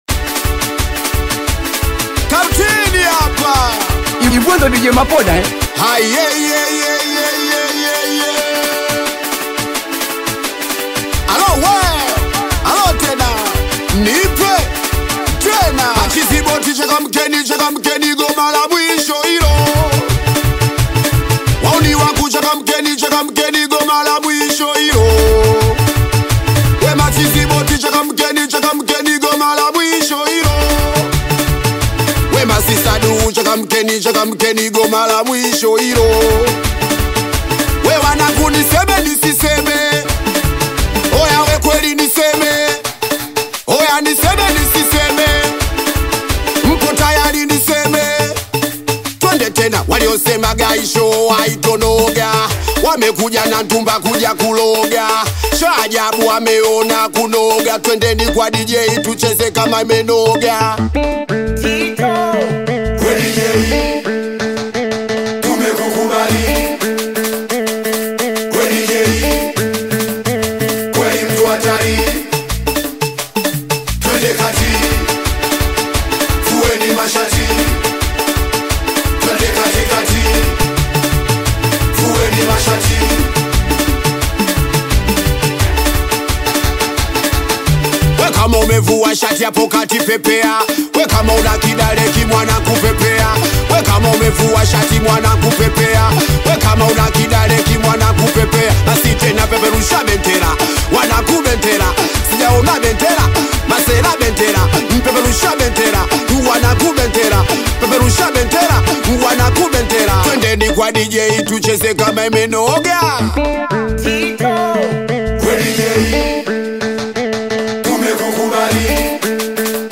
Singeli music track
Bongo Flava
Singeli song